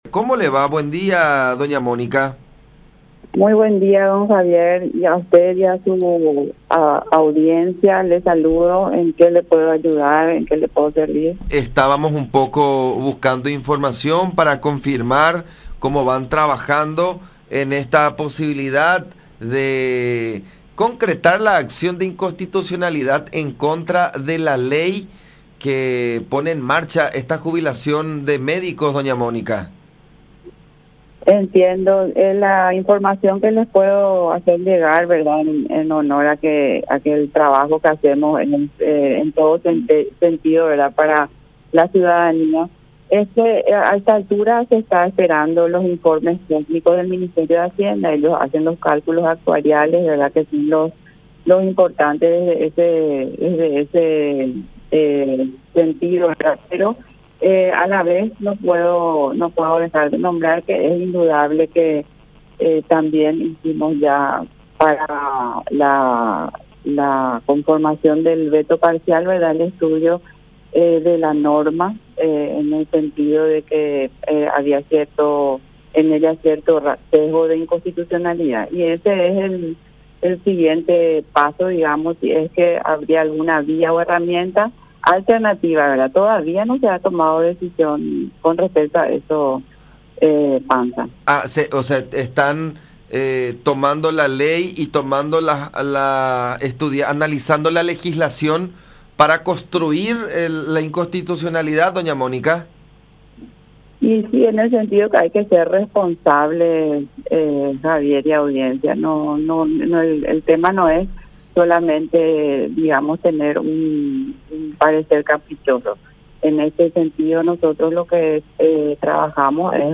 “Todavía no hemos tomado ninguna decisión al respecto”, dijo en comunicación con La Unión, afirmando que están esperando los informes técnicos del Ministerio de Hacienda para manifestar una posición al respecto.